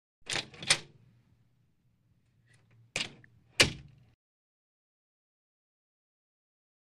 HalfGlassWoodDoor2 PE181301
Half Glass / Wood Door 2; Open And Close.